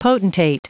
Prononciation du mot potentate en anglais (fichier audio)
Prononciation du mot : potentate